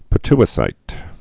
(pĭ-tĭ-sīt, -ty-)